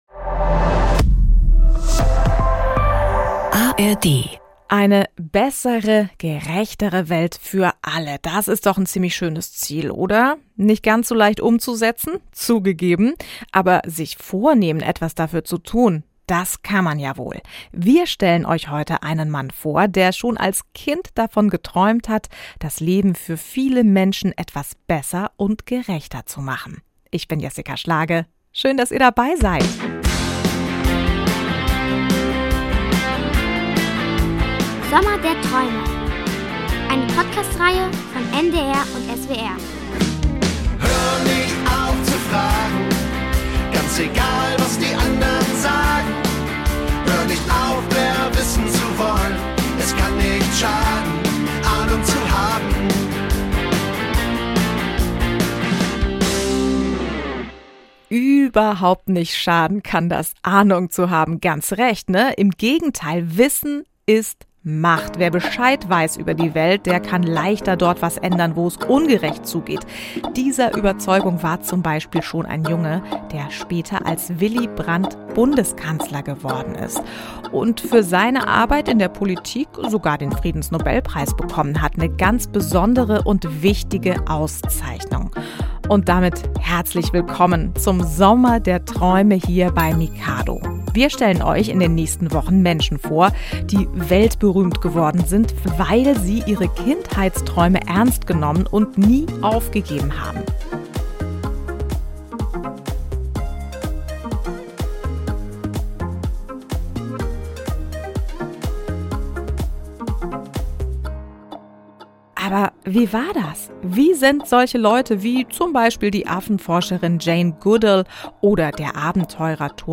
Sommer der Träume: Politiker Willy Brandt ~ Hörspiele, Geschichten und Märchen für Kinder | Mikado Podcast